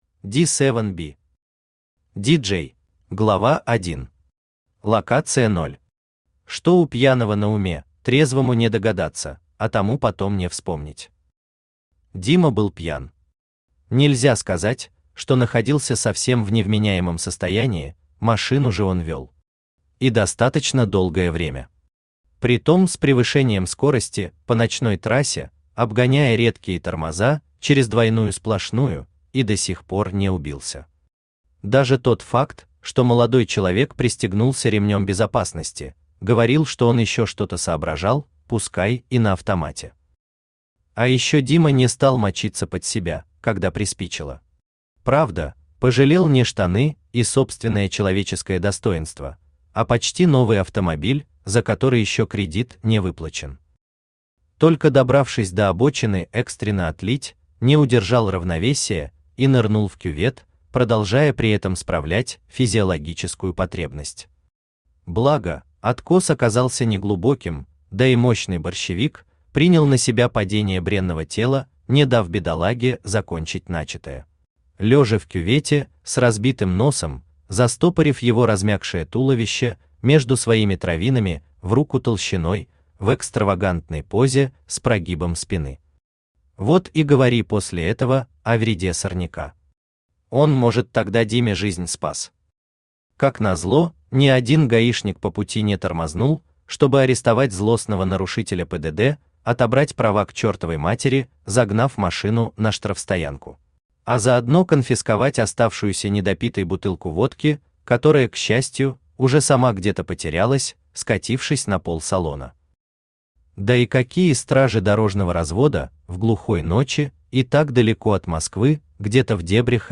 Aудиокнига Ди-Джей Автор Ди Севен Би Читает аудиокнигу Авточтец ЛитРес.